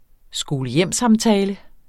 Udtale [ sgoːləˈjεmˀˌsɑmˌtæːlə ]